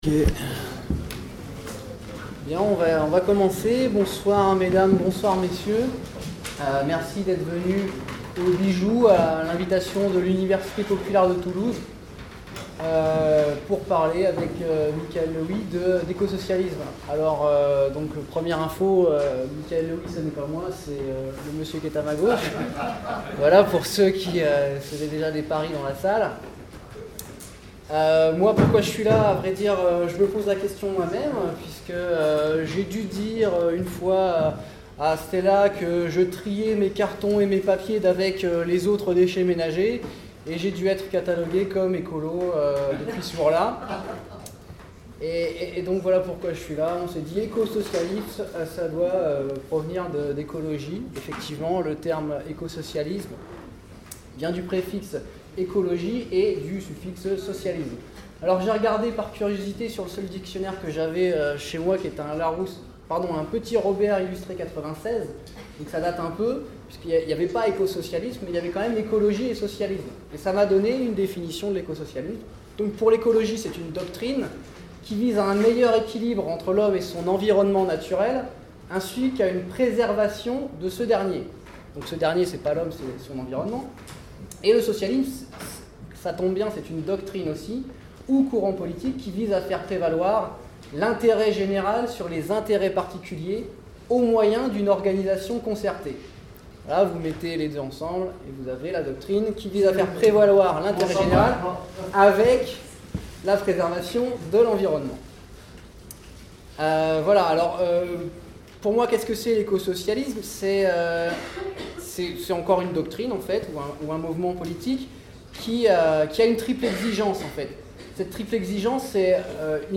L'Université Populaire de Toulouse invite Michael Löwy le mardi 25 février, au Bijou, 123 avenue de Muret, Toulouse, pour une conférence-débat à
Enregistrement sonore de la conférence